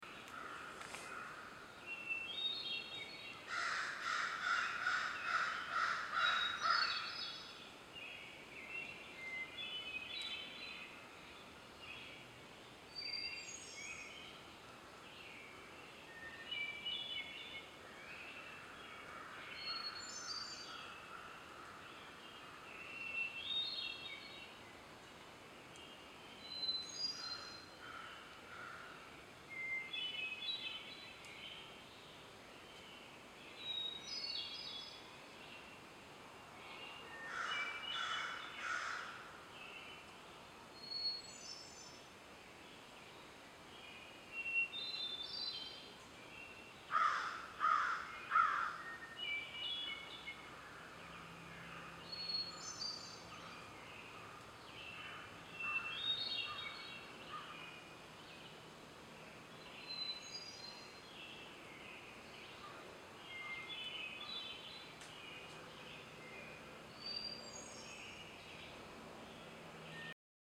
Click here for the sound of the forest awakening. The co-stars are a raucous crow and a hermit thrush. The thrush sings its melancholy melodies each day at dawn and dusk. At dusk there are too many cars roaring around to record it well, but Sunday morning at 4:20 turned out to be a perfect time!
Thrush-and-Crow3.mp3